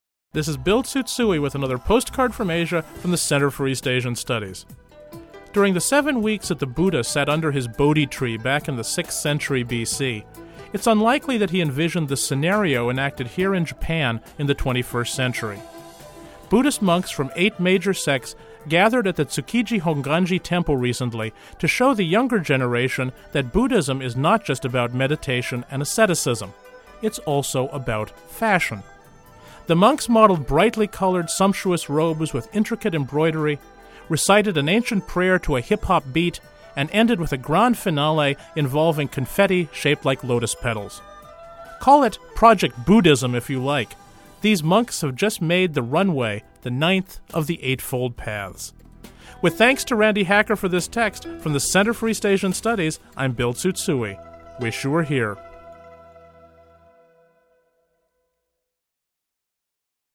Buddhist monks from eight major sects gathered at the Tsukiji Honganji temple recently to show the younger generation that Buddhism is not just about meditation and asceticism: it’s also about fashion. The monks modeled brightly colored, sumptuous robes with intricate embroidery, recited an ancient prayer to a hip hop beat and ended with a grand finale involving confetti shaped like lotus petals.